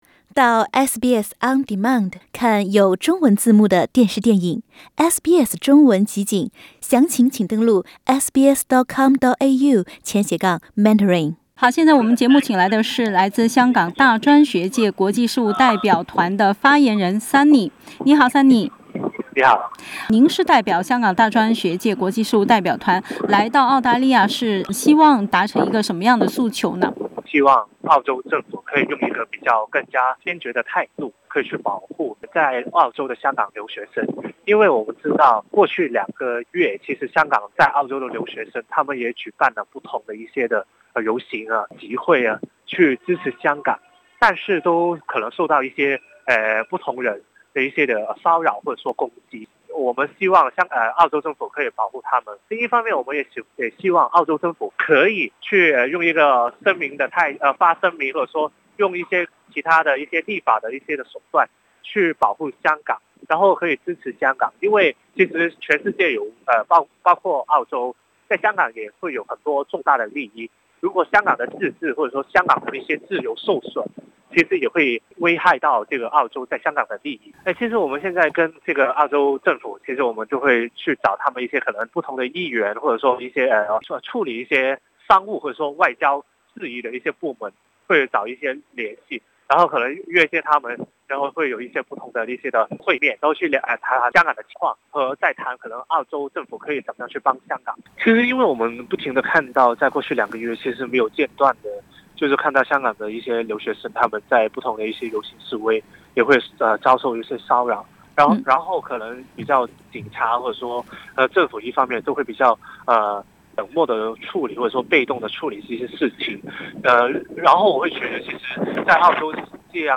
（本节目为嘉宾观点，不代表本台立场。）